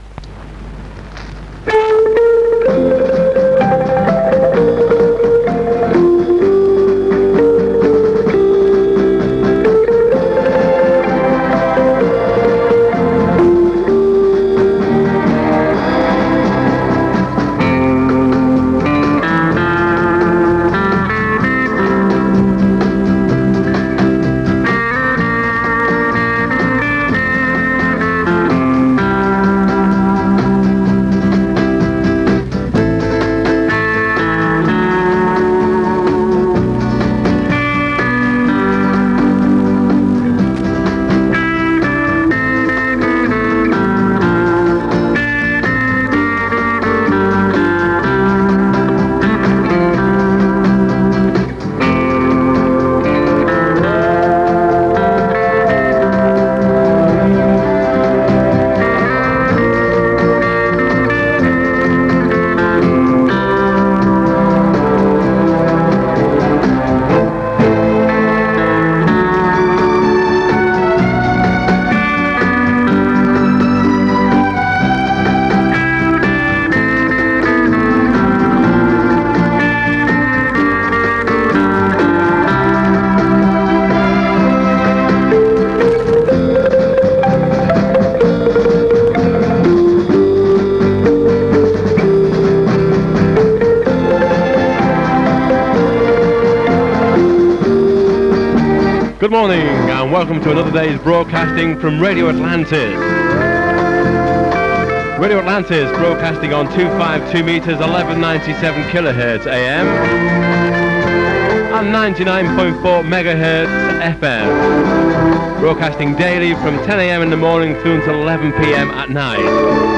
Recorded near Blackpool from 99.4MHz. 3MB